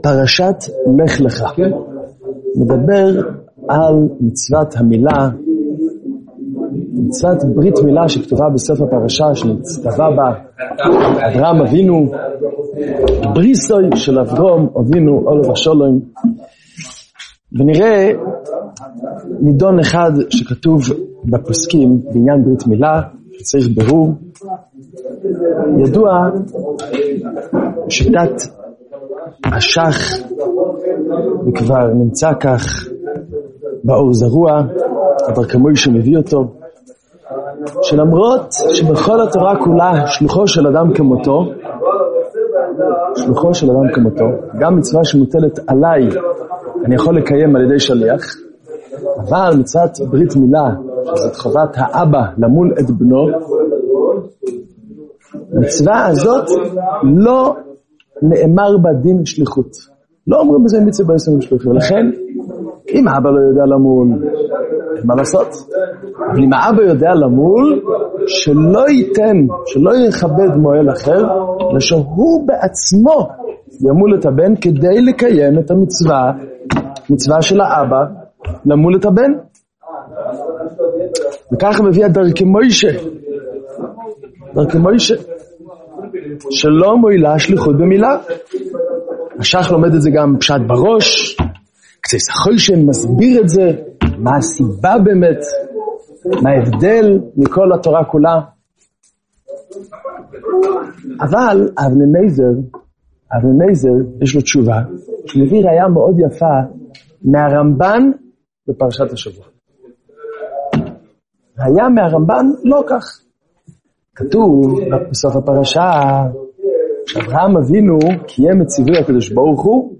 שיעור פרשת לך לך – ביאור השיטות שלא מועילה שליחות במצות מילה